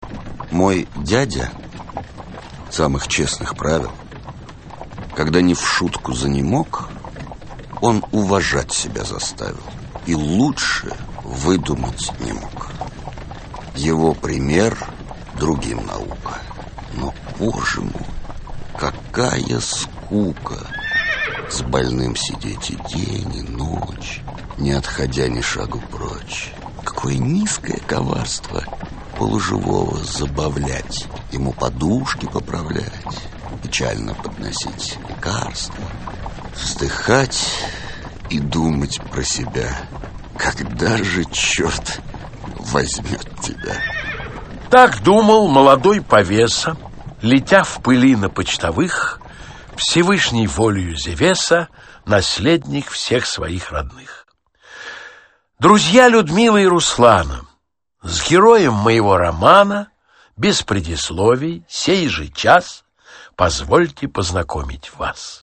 Аудиокнига Евгений Онегин. Аудиоспектакль | Библиотека аудиокниг